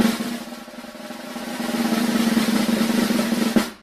sfx_drumroll.ogg